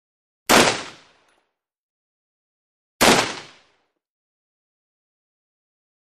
30.06 Rifle: Single Shots ( 2x ); Two High, Single Shots With Shell Falls And Medium Long Echo. Close Up Perspective. Gunshots.